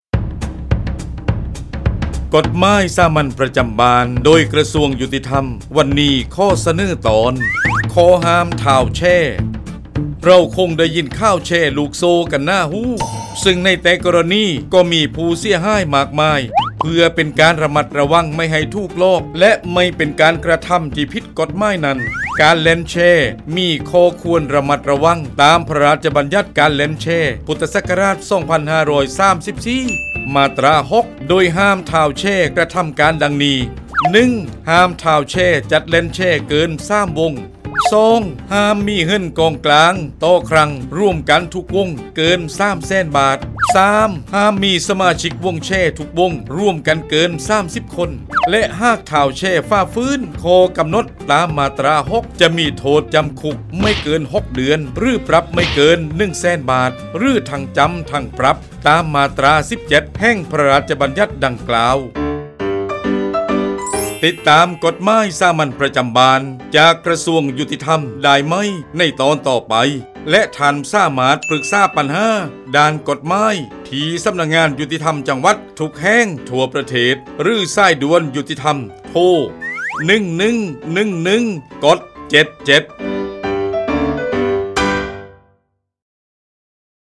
กฎหมายสามัญประจำบ้าน ฉบับภาษาท้องถิ่น ภาคใต้ ตอนข้อห้ามท้าวแชร์
ลักษณะของสื่อ :   บรรยาย, คลิปเสียง